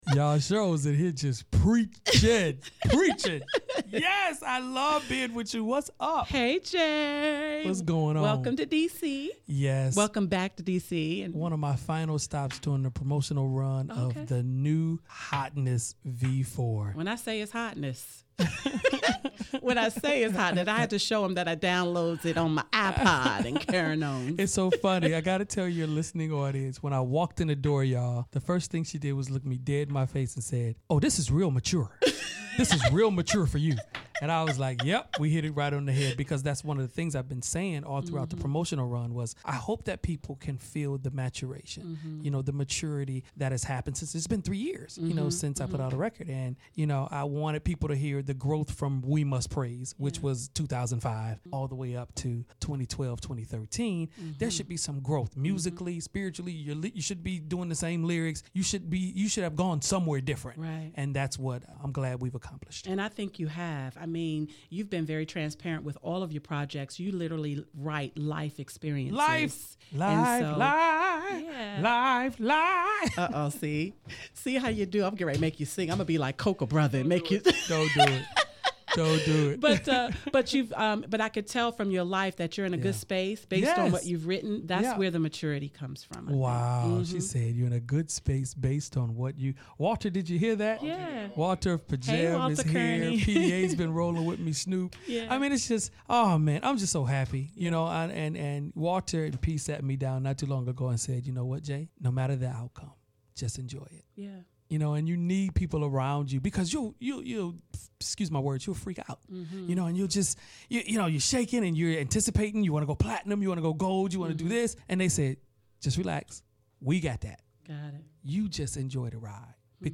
Hanging out with J Moss in the Praise 104.1 Studio